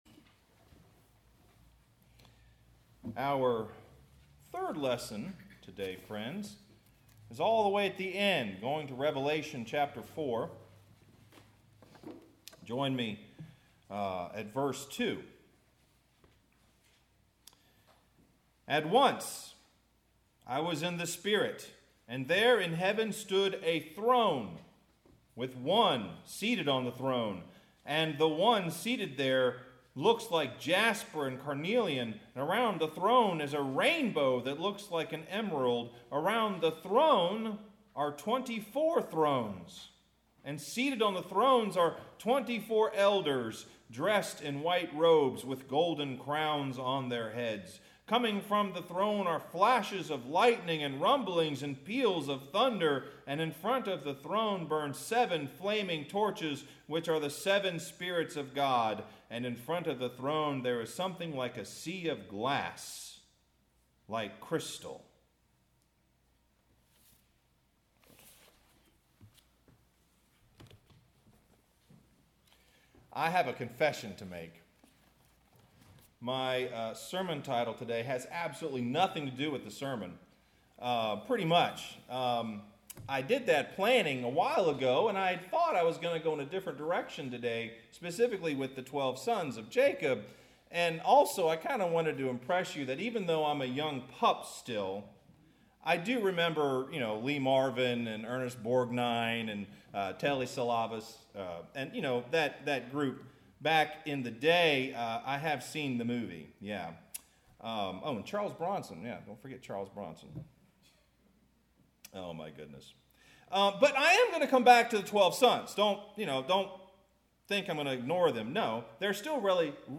Genesis 35:1-29; Matthew 10: 1-8; Revelation 4:2-6a March 20, 2022 Jacob and his 12 sons move his life into the promise of God I need to tell you right off the bat that this sermon title really does not work for this sermon.